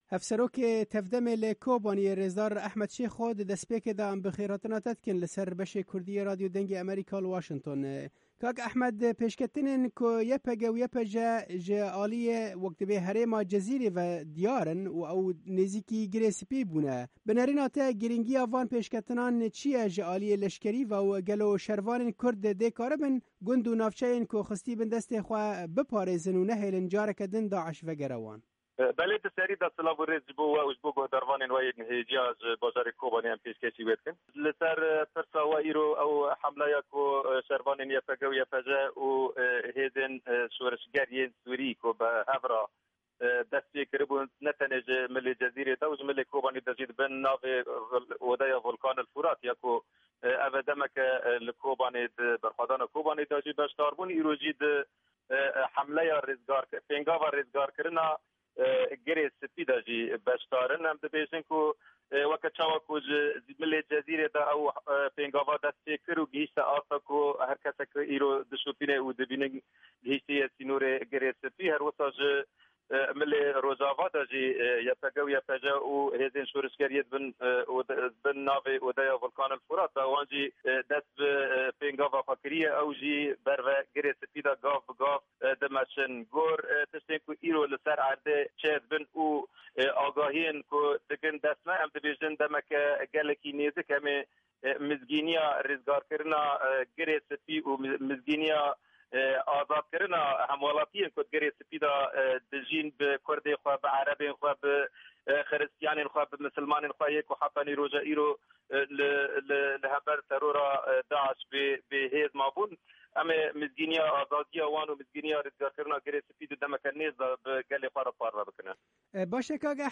Hevserokê TEV-DEM li Kobaniyê rêzdar Ehmed Șêxo, ji Dengê Amerîka re pêșketinên dawî yên șer li wir șîrove kirin û got: